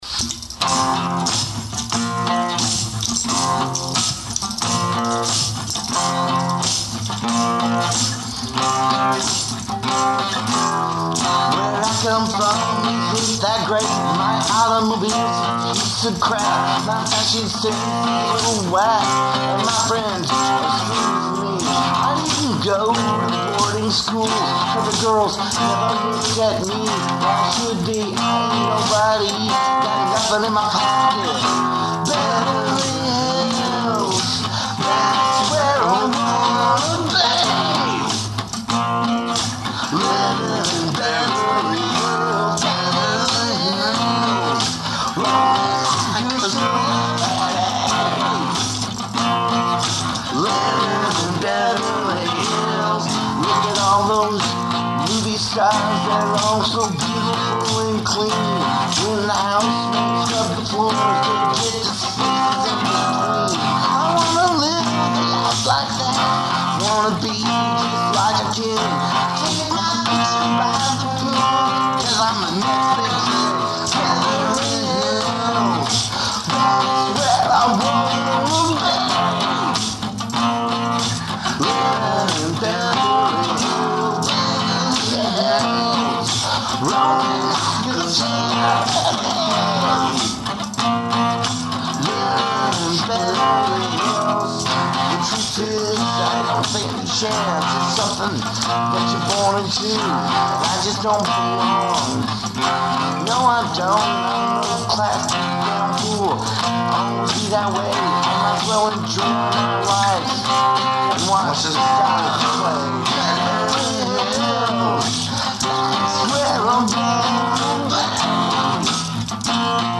Dork Rock